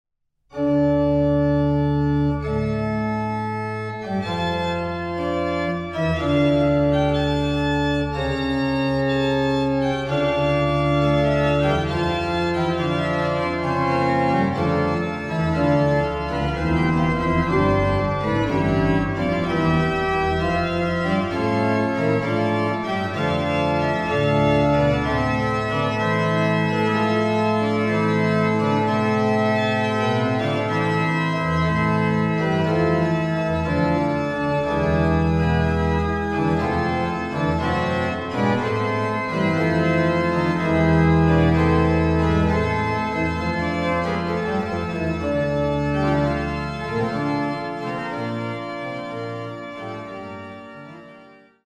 1755 erbaut für Prinzessin Anna Amalia von Preußen